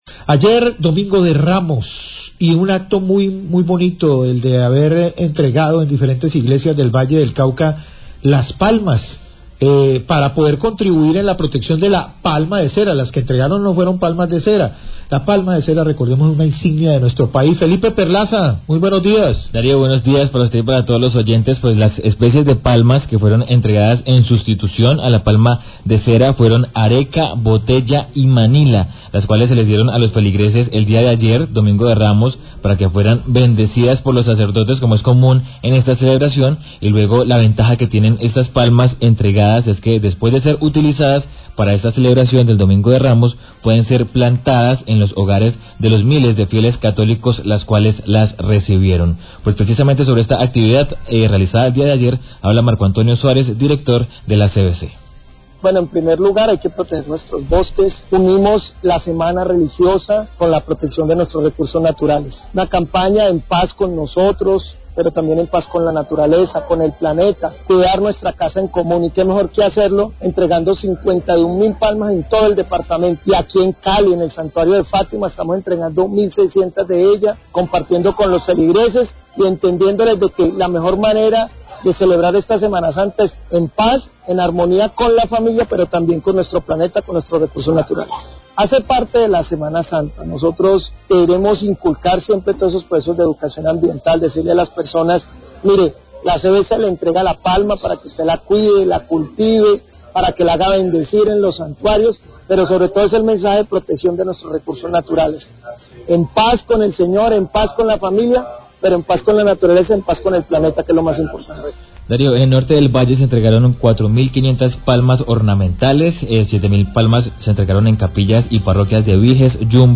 Dir. CVC habla de entrega de palmas areca en el Domingo de Ramos
Radio
La CVC entregó cerca de 55 mil palmas areca, bottela y manila este Domingo de Ramos en varias parroquias del departamento para celebrar el Domingo de Ramos y así no afectar la palma de cera. Habla del director general de la CVC, Marco Antonio Suárez.